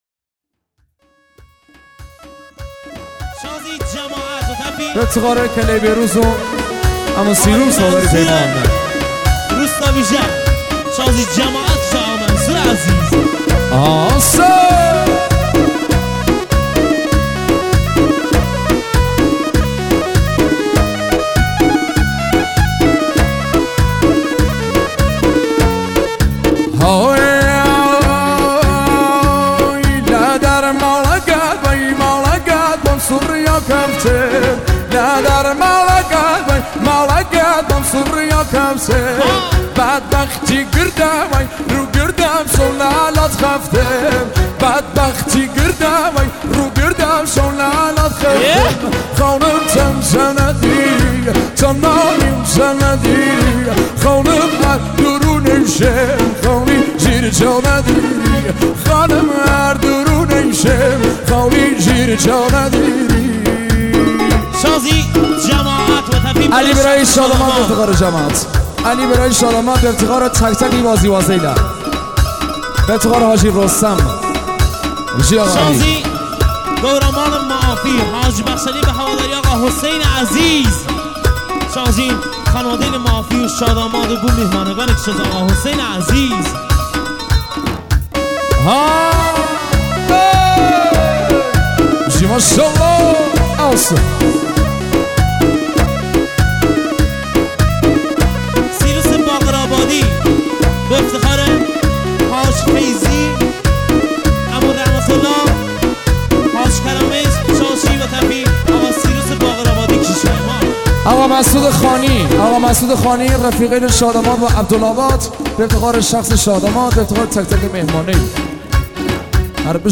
اجرا در عروسی ها